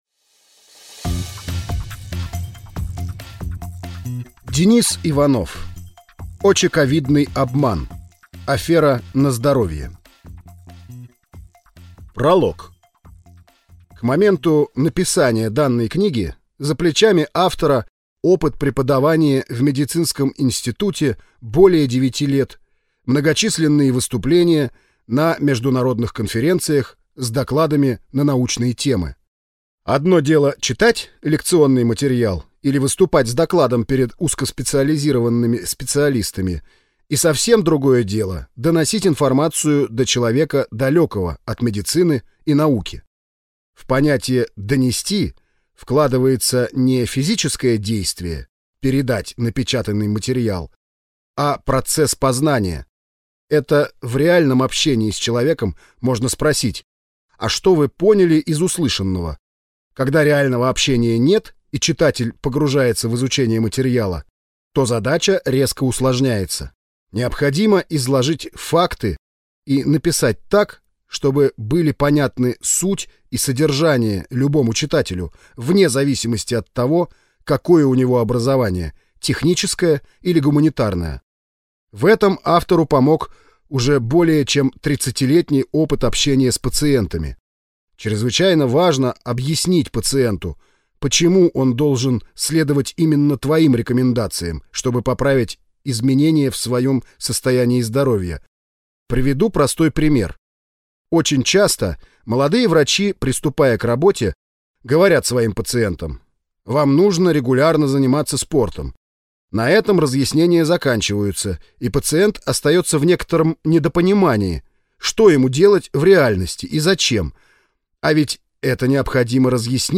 Аудиокнига ОчеCOVIDный обман. Афера на здоровье | Библиотека аудиокниг